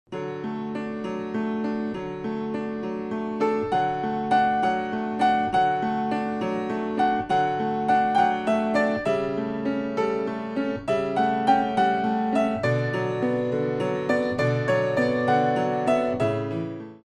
Classical Arrangements for Pre Ballet Classes